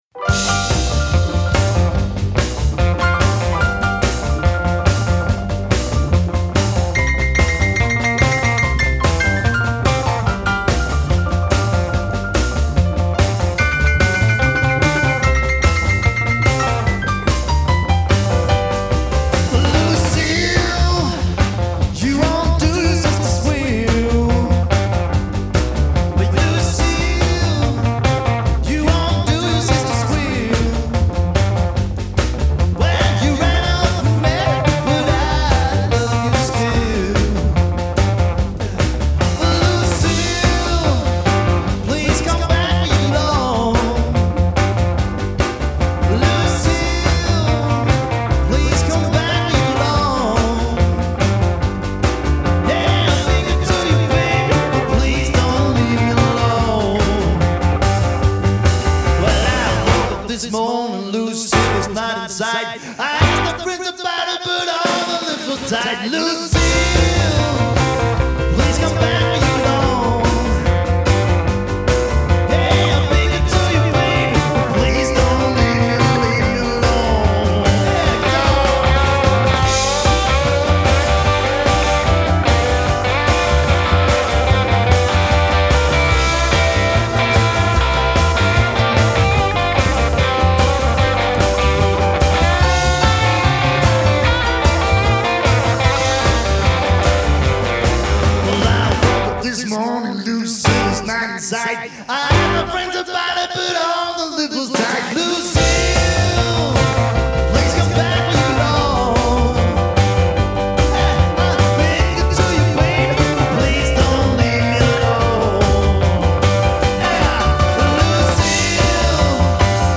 The greatest rock 'n roll of the 50's and 60's